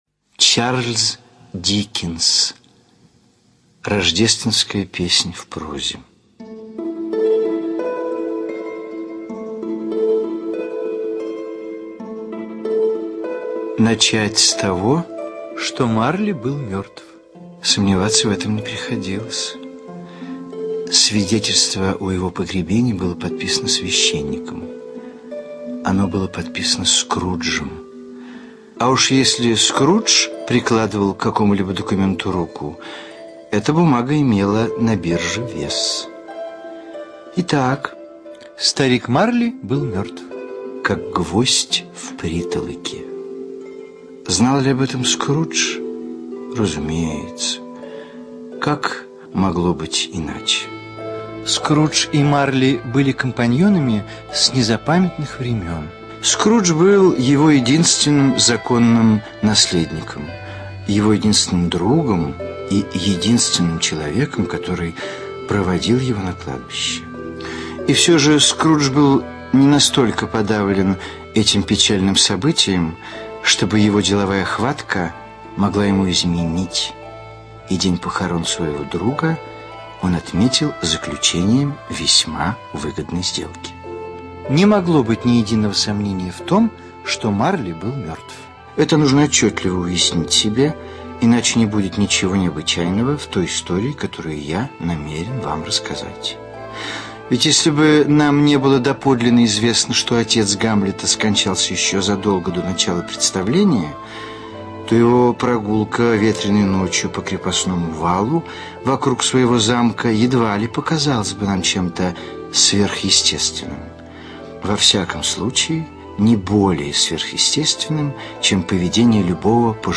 ЧитаетГвоздицкий В.